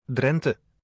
pronounciation of Dutch province Self made, in own studio, with own voice